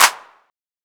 MZ Clap [Southside #2].wav